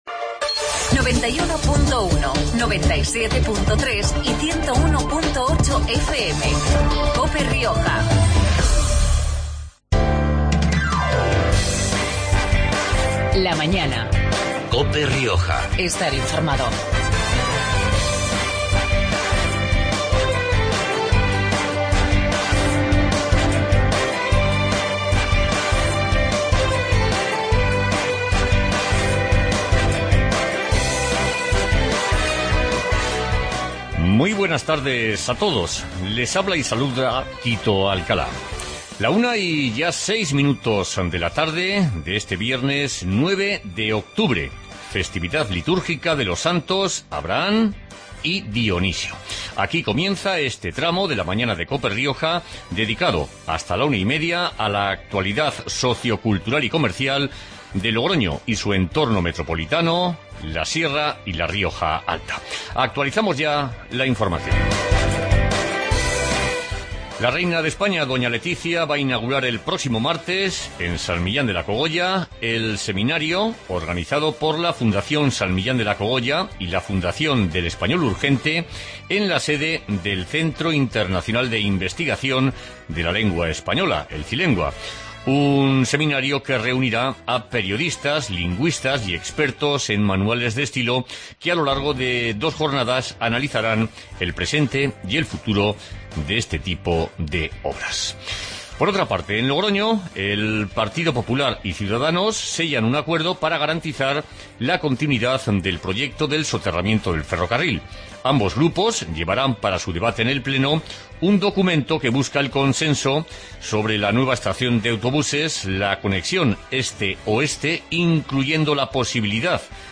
Magazine de actualidad